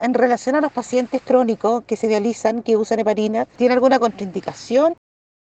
Auditores de Radio Bío Bío hicieron llegar sus consultas.